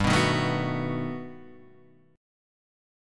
G+M9 chord {3 2 1 2 0 2} chord